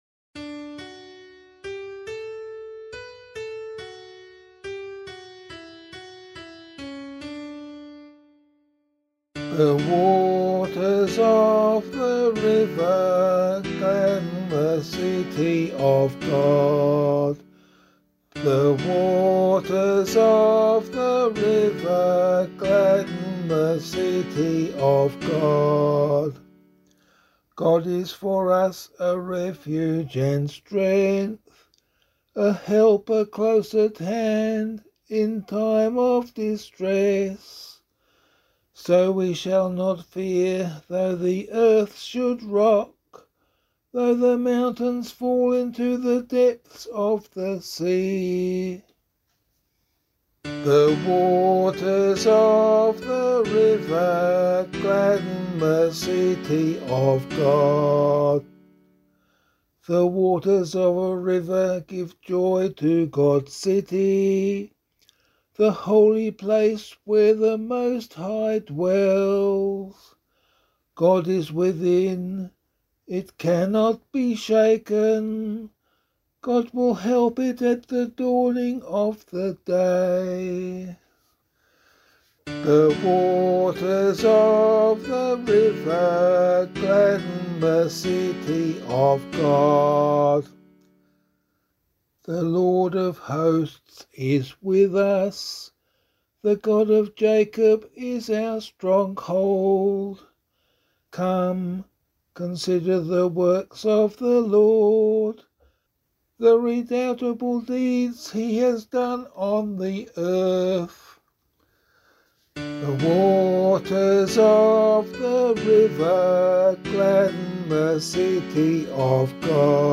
413 Lateran Basilica Psalm [LiturgyShare 5 - Oz] - vocal.mp3